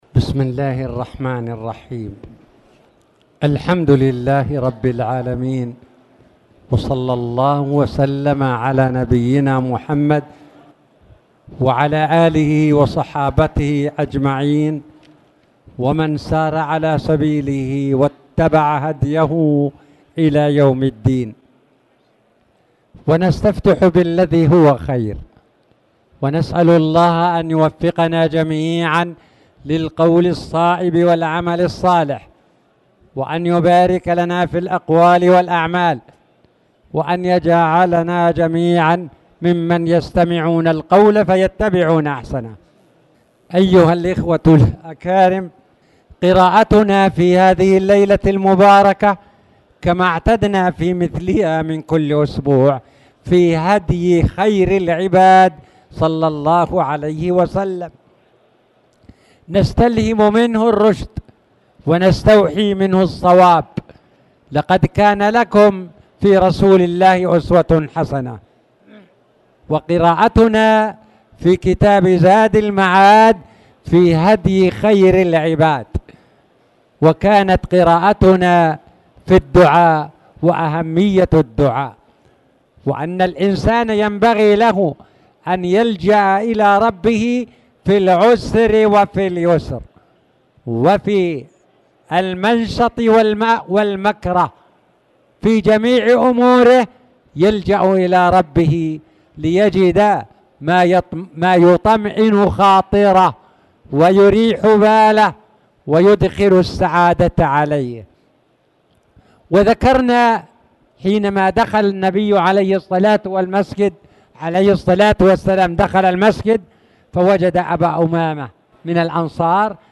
تاريخ النشر ١٧ جمادى الآخرة ١٤٣٨ هـ المكان: المسجد الحرام الشيخ